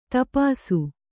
♪ dūpisu